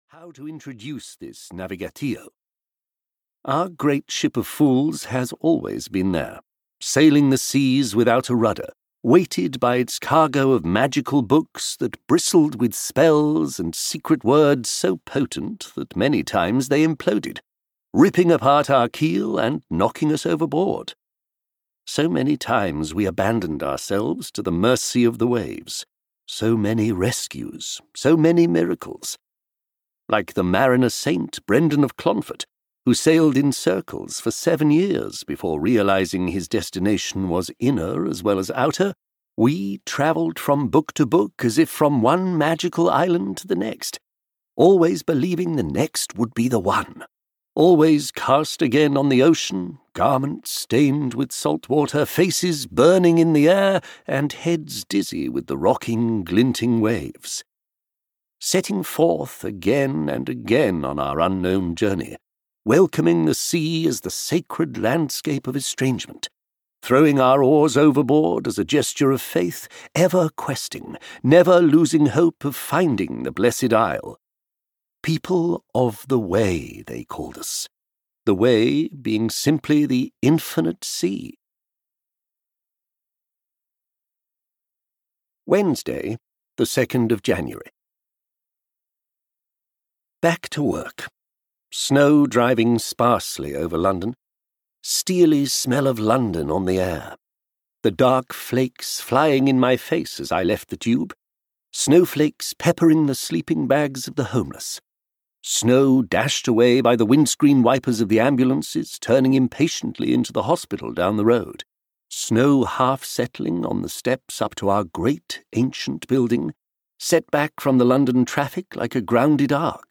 The House of Marvellous Books (EN) audiokniha
Ukázka z knihy